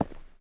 mining sounds
ROCK.4.wav